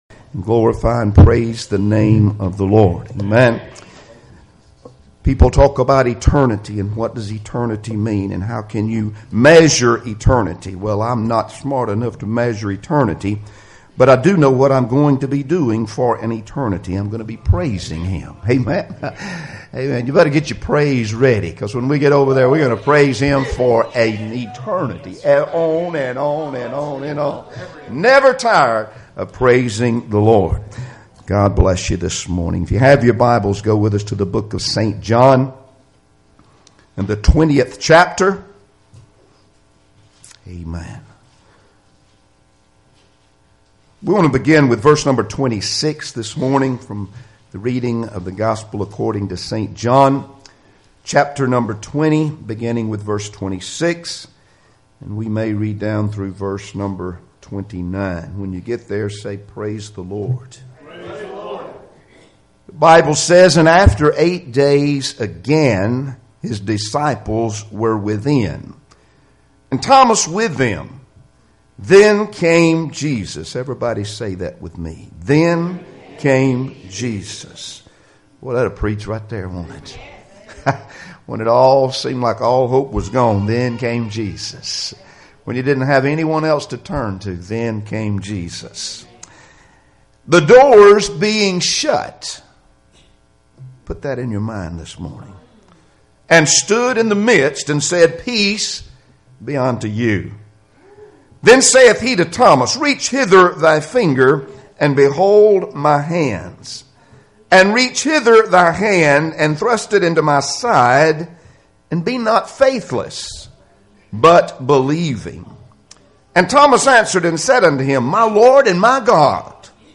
John 20:26-29 Service Type: Sunday Morning Services Topics